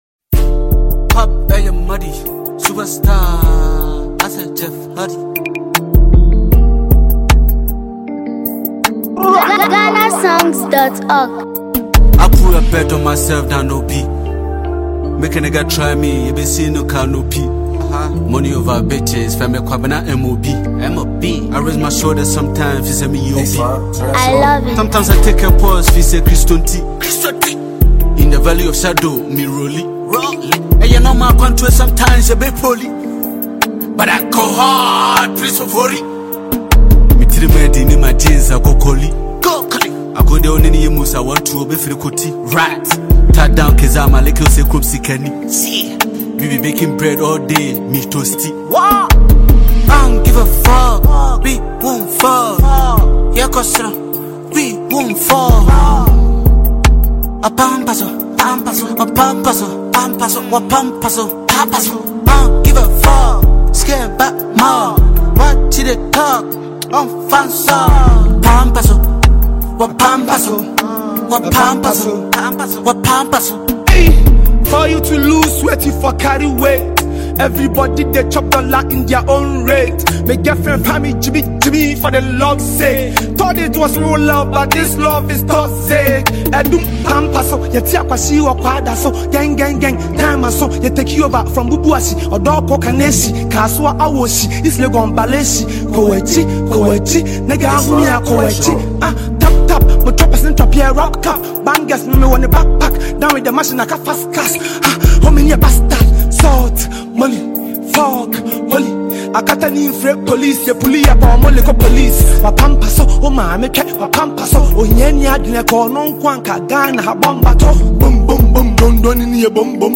Ghanaian rapper
is driven by a hard-hitting beat
With its strong delivery and infectious rhythm